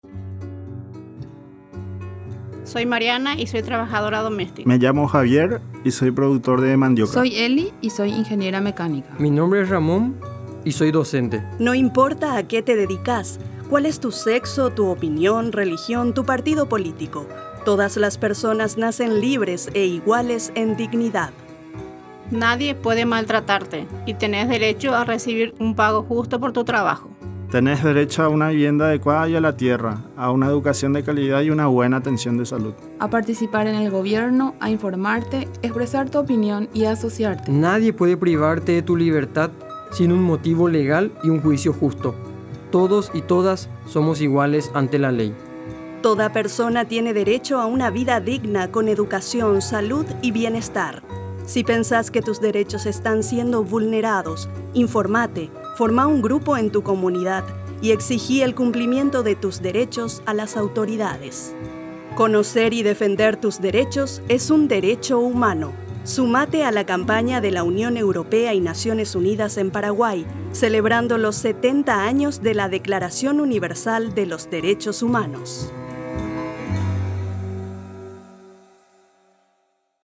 Spots Radiales